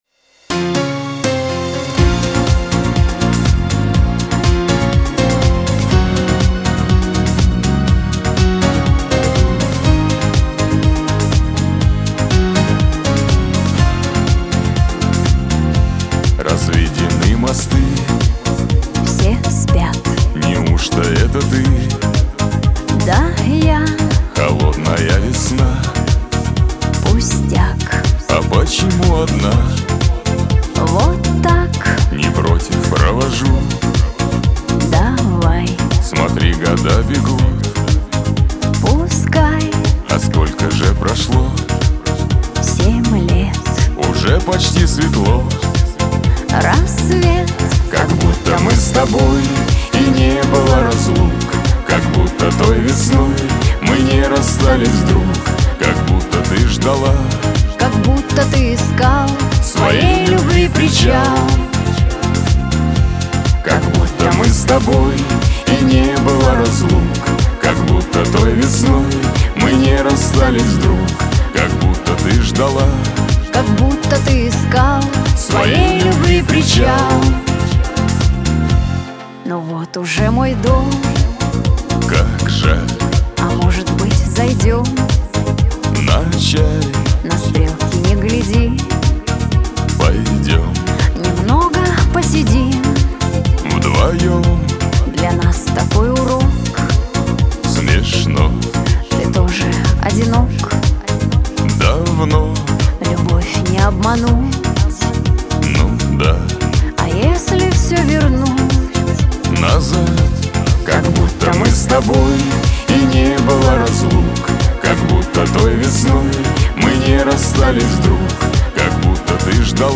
Трек размещён в разделе Русские песни / Шансон.